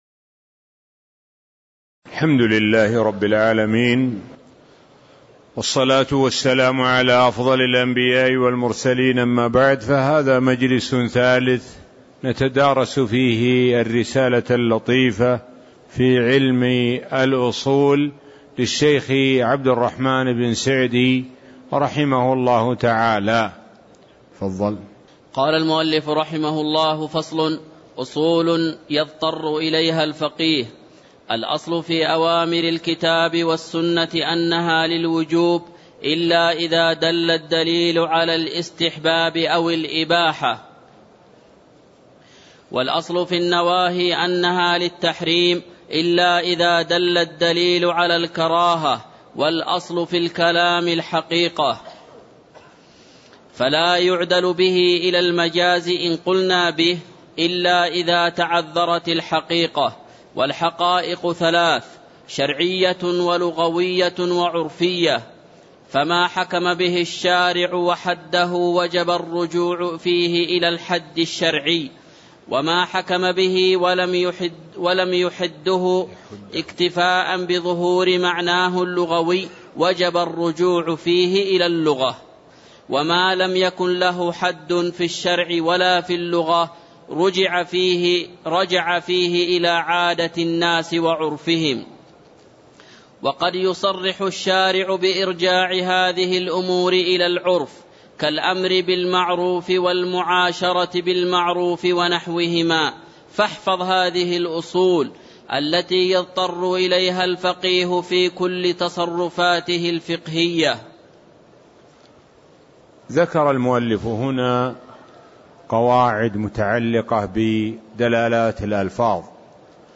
تاريخ النشر ٩ شوال ١٤٣٦ هـ المكان: المسجد النبوي الشيخ: معالي الشيخ د. سعد بن ناصر الشثري معالي الشيخ د. سعد بن ناصر الشثري فصل أصول يضطر إليها الفقيه (002)